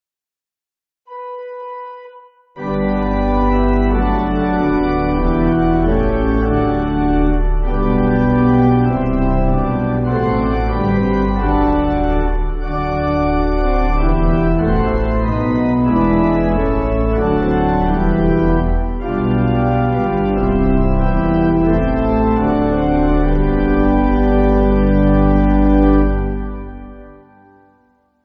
(CM)   1/G
Single Note intro.